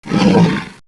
Heroes3_-_Royal_Griffin_-_AttackSound.ogg